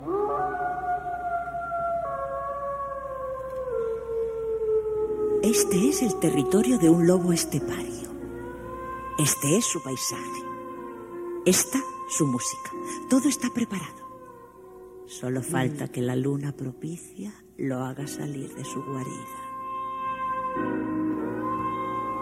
Careta del programa.
FM